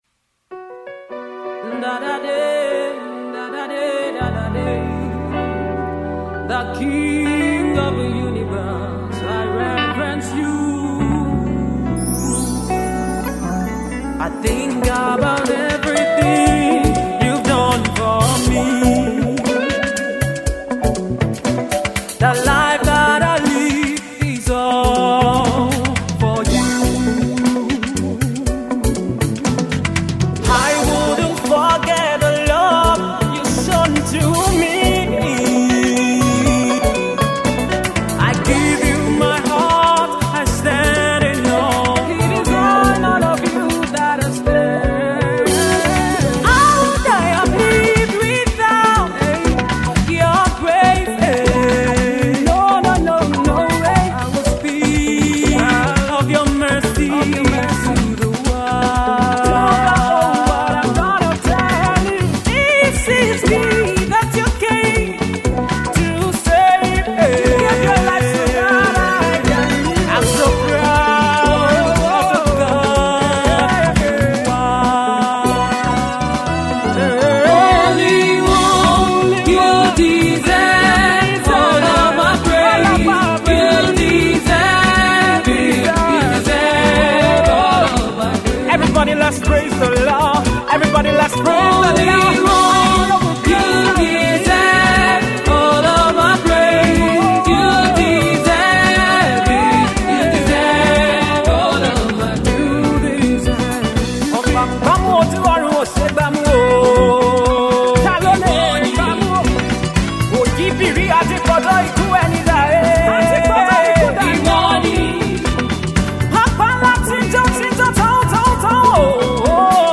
African Praise song blended with African Instrumentation